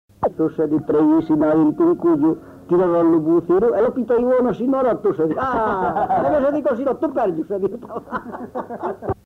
Aire culturelle : Périgord
Genre : forme brève
Effectif : 1
Type de voix : voix d'homme
Production du son : parlé
Classification : formulette